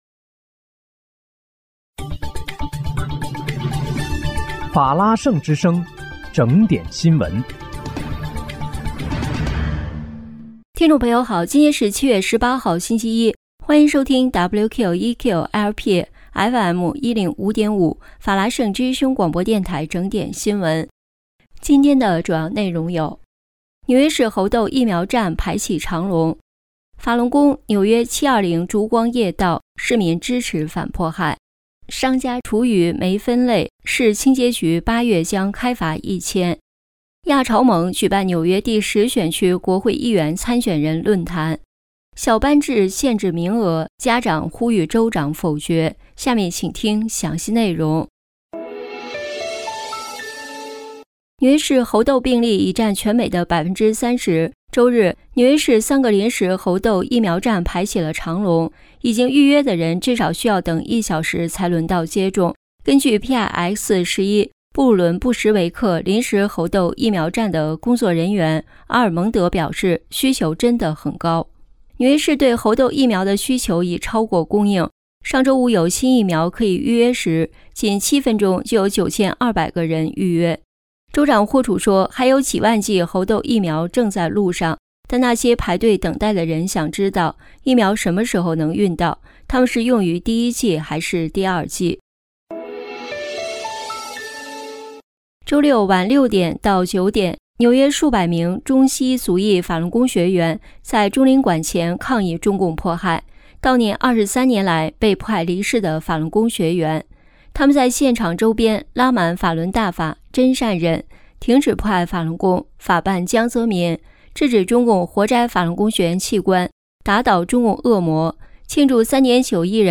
7月18日（星期一）纽约整点新闻